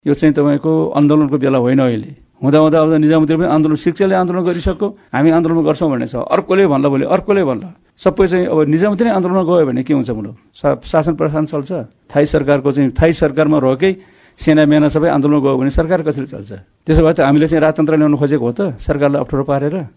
मन्त्रिपरिषद्का निर्णय सार्वजनिक गर्न मन्त्रालयमा आयोजित पत्रकार सम्मेलनमा उनले शिक्षकको मात्र तलब बढाएर नहुने भन्दै देशको आर्थिक अवस्था पनि बुझिदिन आग्रह गरे।